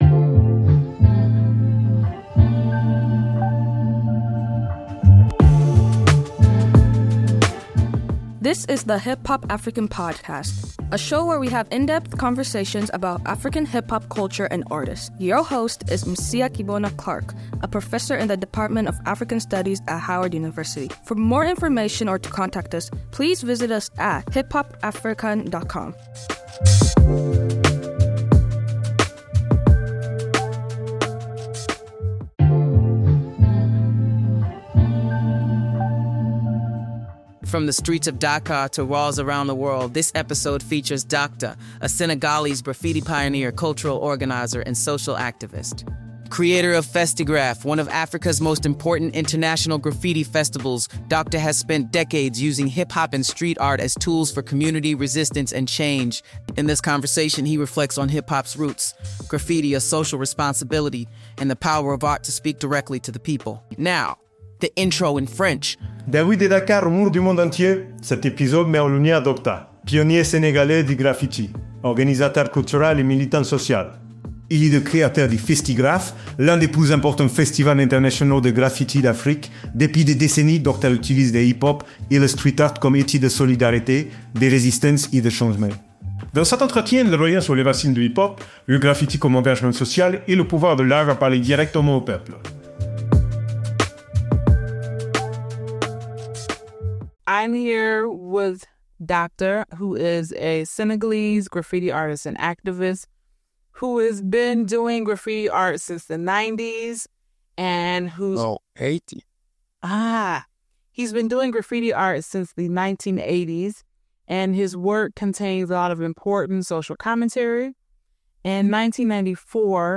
A Hip-Hop African conversation